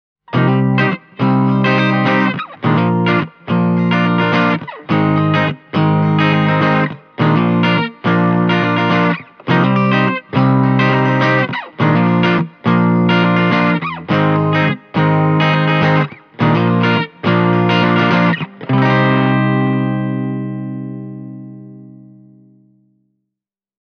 Probably clean tones with a dry character and not very much headroom.
The higher output of my Gibson Les Paul Junior’s P-90 clearly drives the preamp more, which results in a nice bit of added graininess at the same settings, especially in the attack phase of each note:
marshall-offset-e28093-junior-clean.mp3